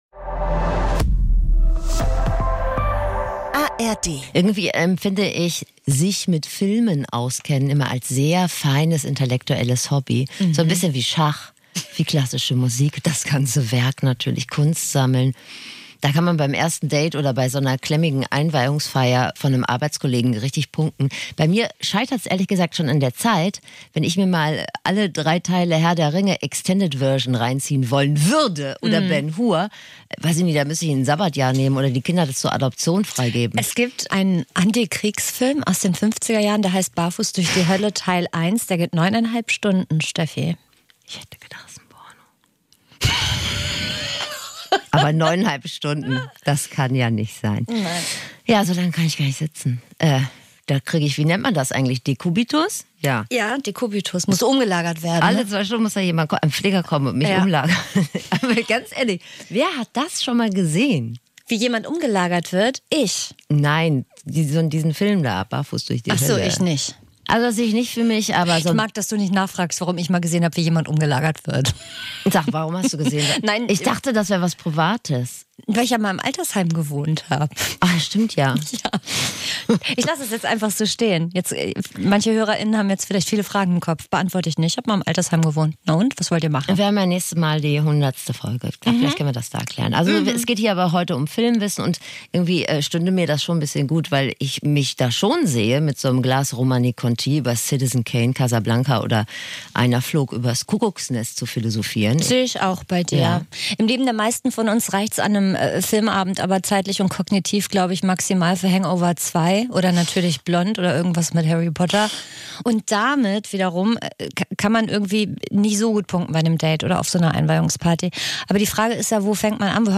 Dieser Laber-Podcast mit Bildungsauftrag versorgt Euch mit Klugscheißerwissen, mit dem Ihr ordentlich flexen könnt.